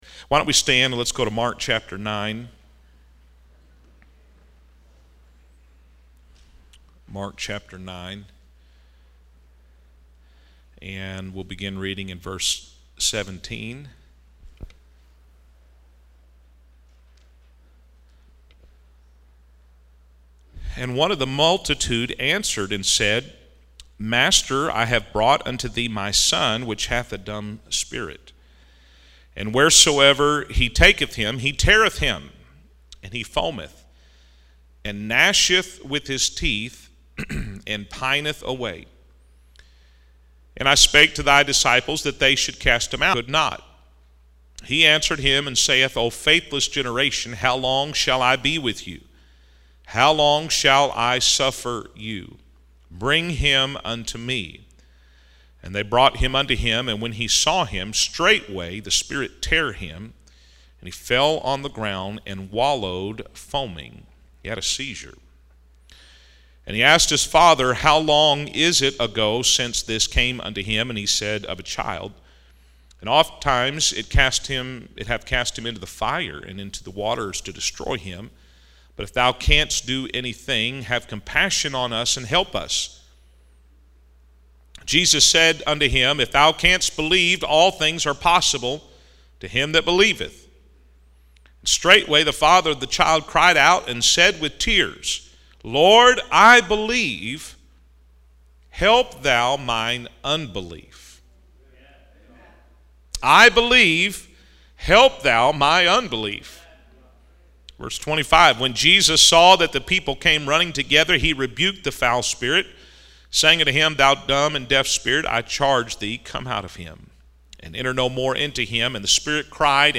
This sermon offers hope and practical guidance for those striving to deepen their faith amidst life's challenges.